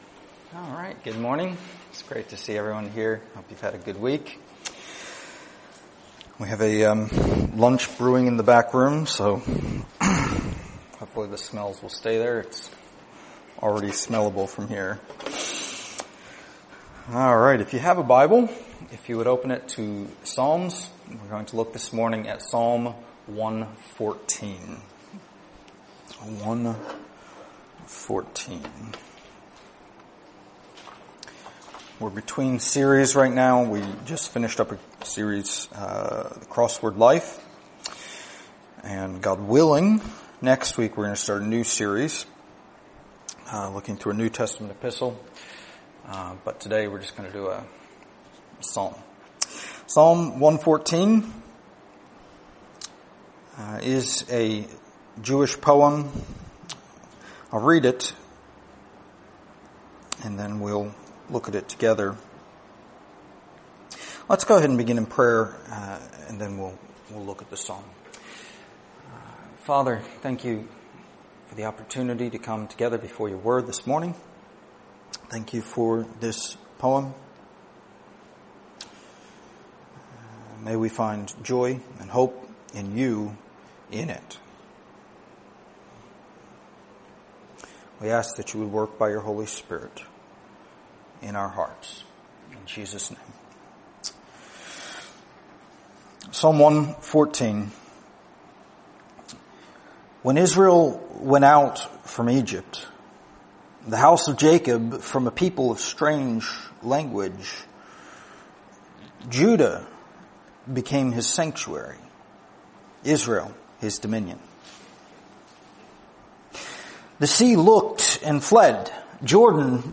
Series: Stand Alone Sermons